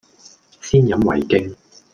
Голоса - Гонконгский 430